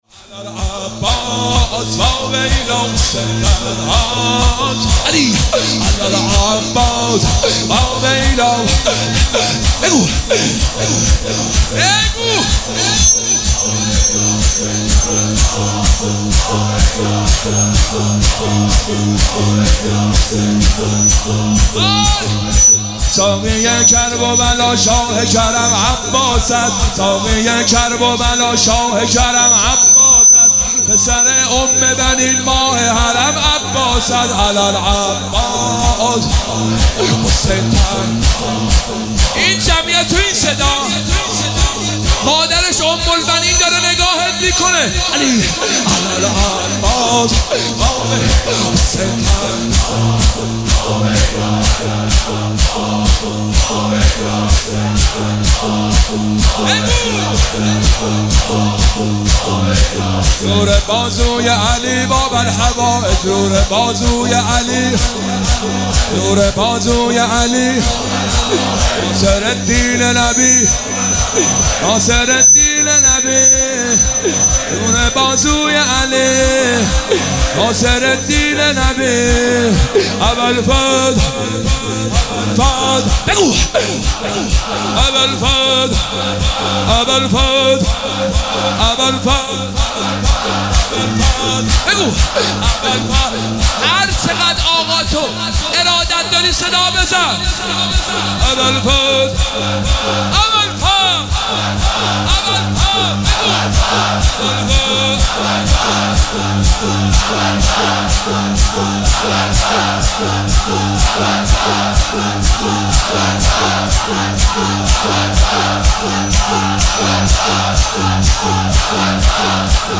محفل عزاداری شب نهم محرم